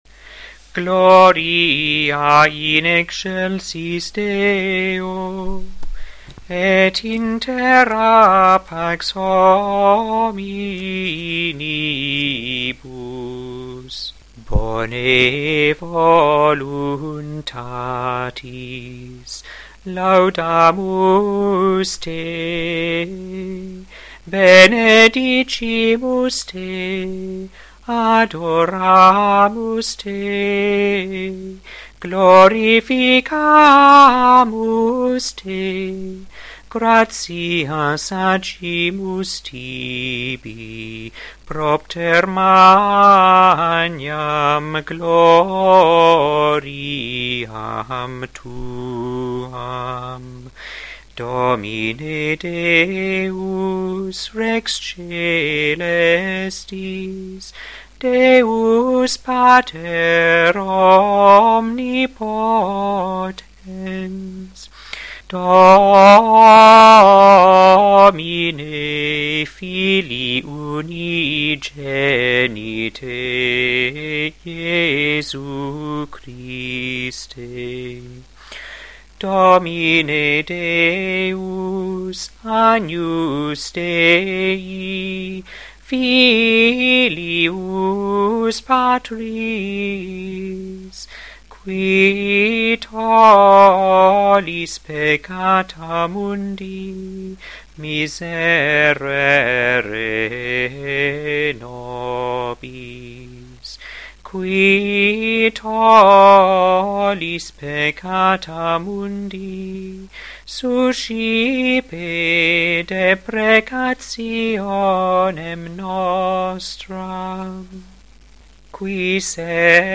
2. Gregorian Chant